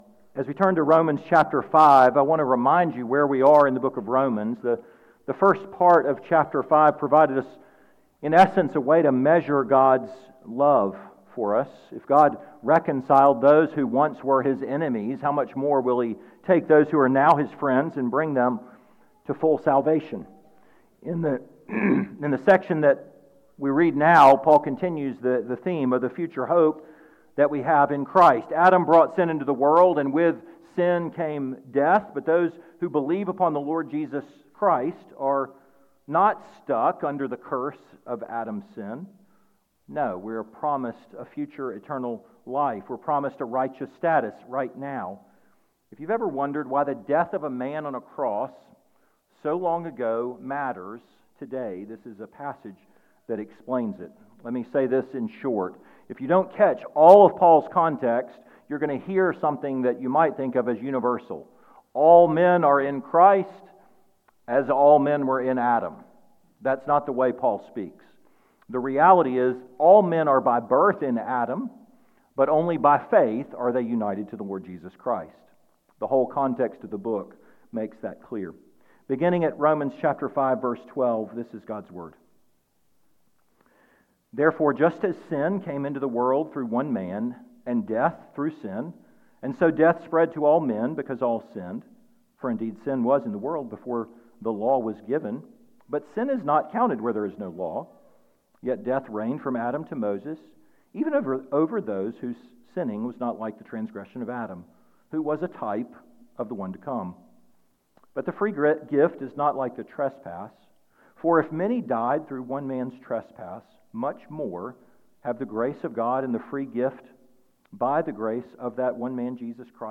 2026 Your Family Line Preacher